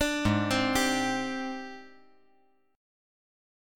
G#M#11 Chord
Listen to G#M#11 strummed